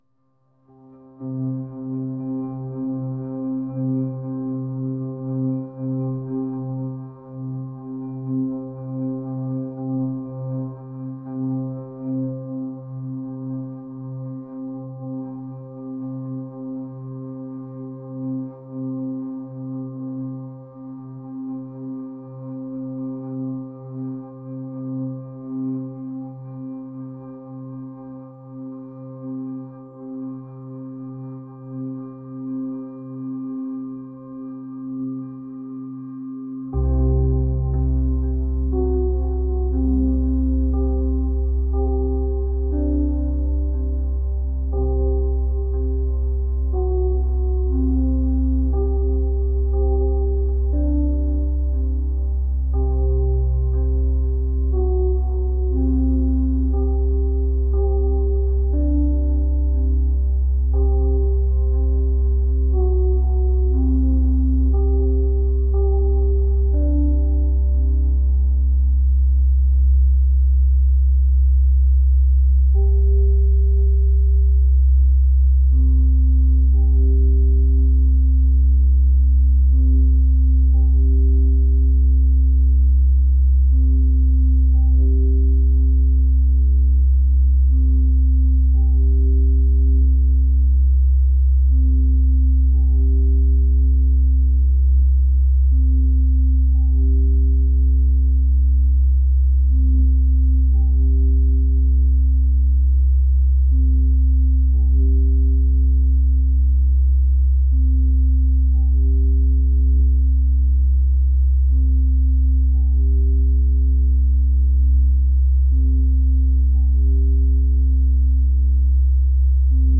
atmospheric